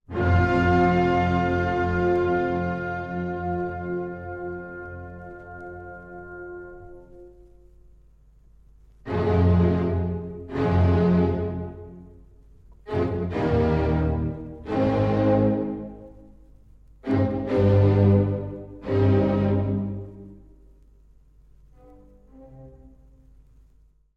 ↑古い音源なので聴きづらいかもしれません！（以下同様）
舞踏会で使われるのですが、この曲では重々しい拍子感が特徴です。
ひじょうに鬱屈とした雰囲気です…！